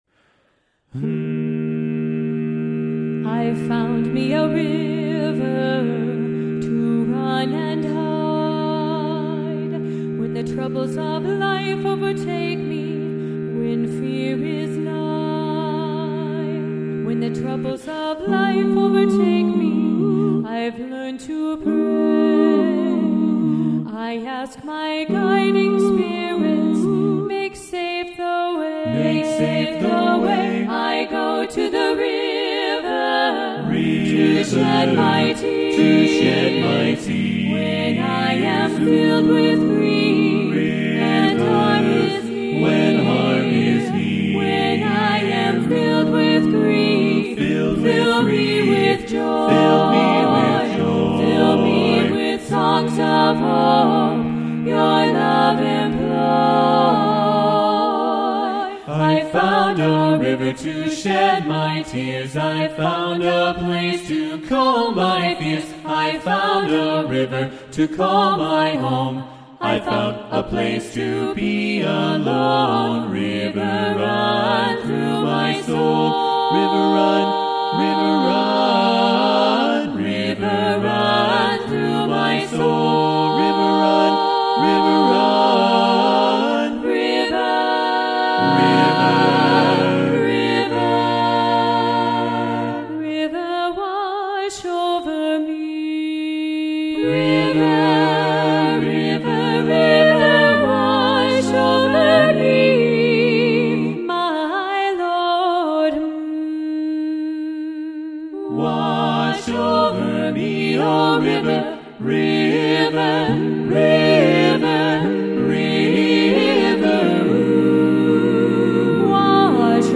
Voicing: SATB double choir a cappella